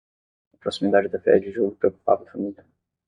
Read more (feminine) proximity, nearness (feminine) neighborhood, vicinity Frequency C2 Pronounced as (IPA) /pɾo.si.miˈda.d͡ʒi/ Etymology Borrowed from Latin proximitas In summary Borrowed from Latin proximitātem.